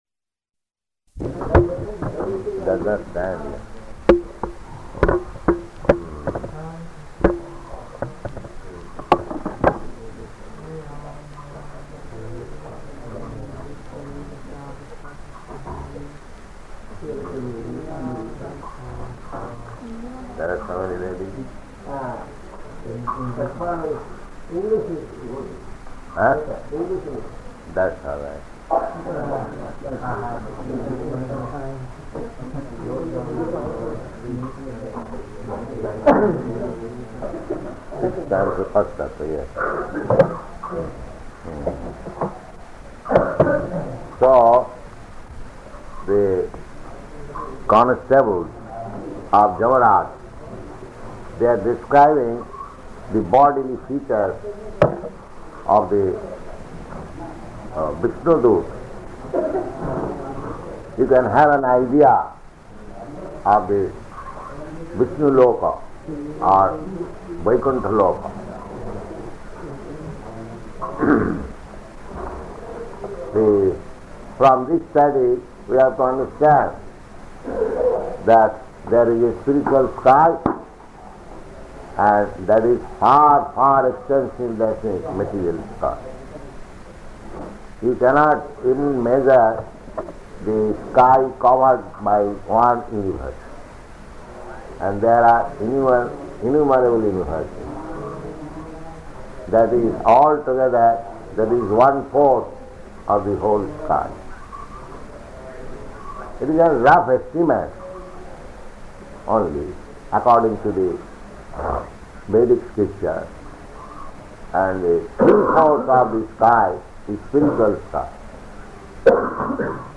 Śrīmad-Bhāgavatam 6.1.34–39 --:-- --:-- Type: Srimad-Bhagavatam Dated: December 19th 1970 Location: Surat Audio file: 701219SB-SURAT.mp3 Prabhupāda: [referring to microphone] It does not stand here.